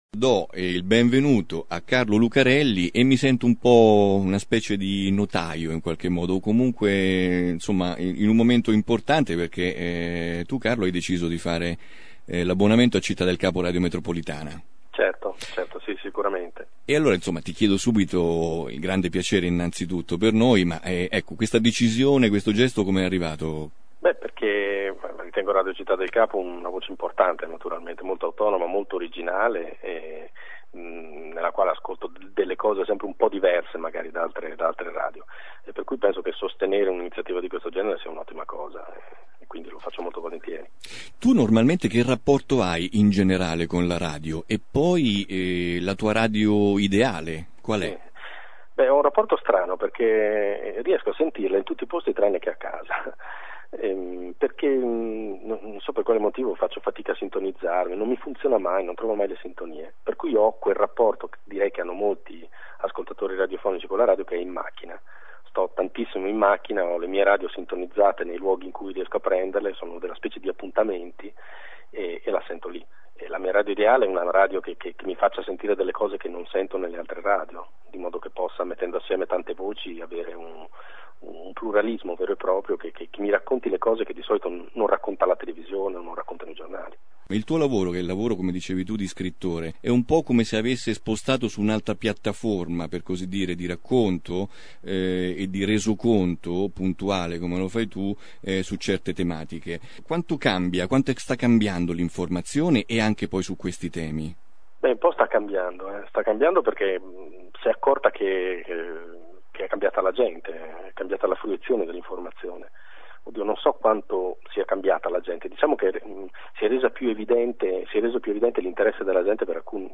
Scrittore, giallista, giornalista: l’autore di Blu Notte si è abbonato oggi. Ascolta l’estratto dell’intervista: carlo_lucarelli_sito